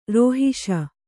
♪ rōhiṣa